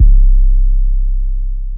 808 - Metro.wav